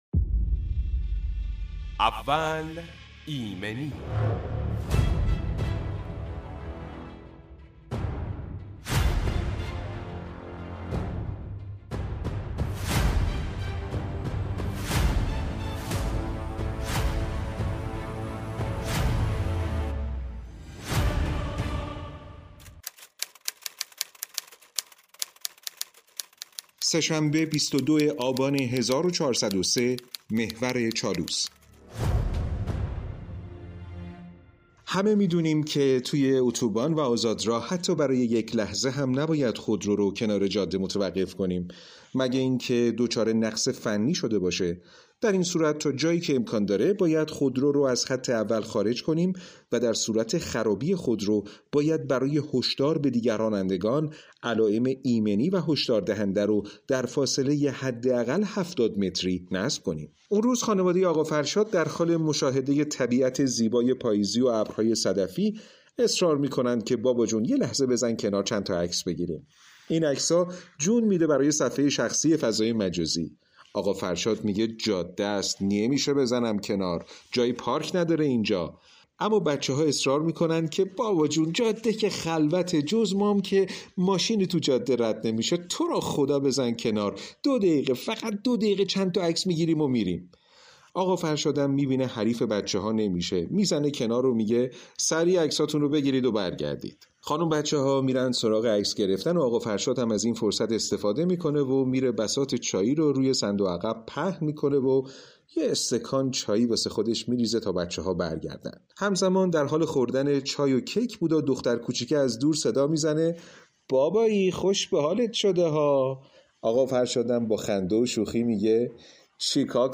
سه شنبه 22 آبان 1403 – محور چالوس– (صدای کیبورد)
برنامه اول ایمنی به مدت ۱۵ دقیقه با حضور کارشناس متخصص آغاز و تجربیات مصداقی ایمنی صنعتی به صورت داستانی بیان می شود.